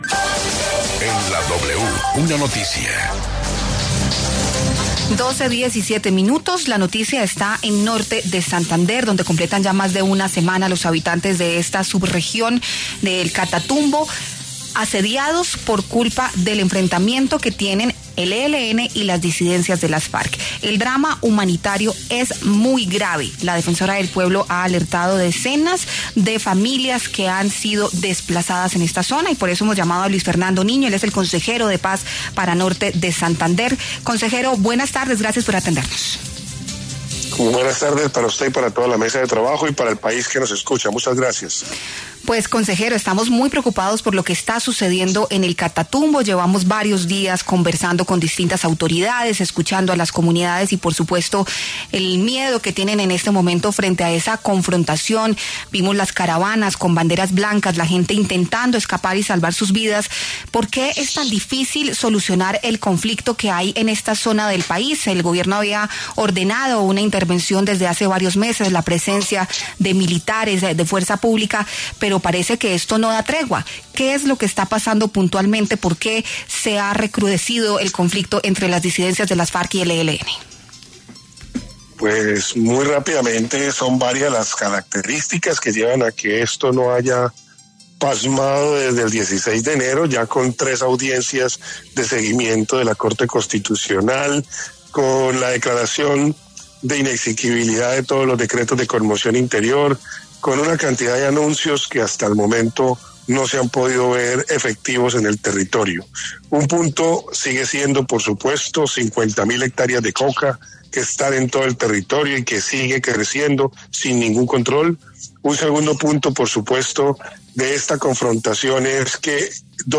Luis Fernando Niño, consejero de Paz para Norte de Santander, conversó con La W acerca del recrudecimiento del conflicto en el Catatumbo, Norte de Santander, entre el Ejército de Liberación Nacional (ELN) y las disidencias de las Farc.